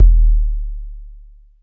DWS KICK2 -R.wav